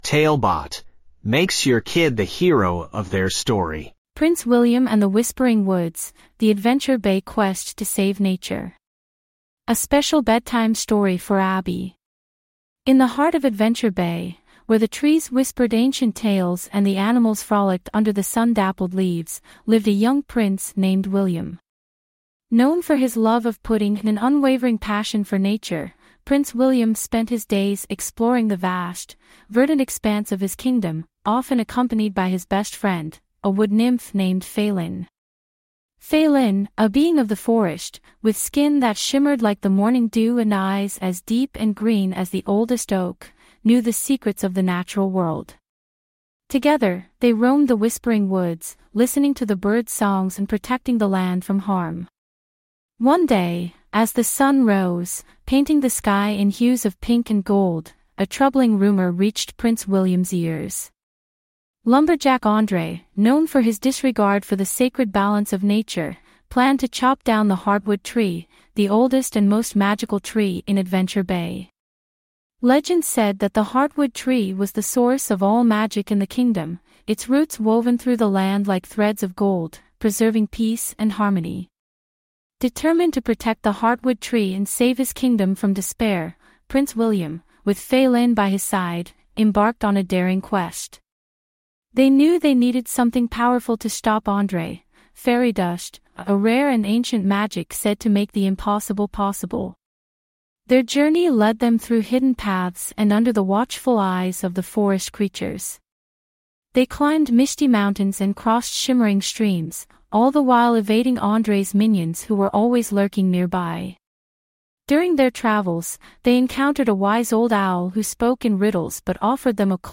5 Minute Bedtime Stories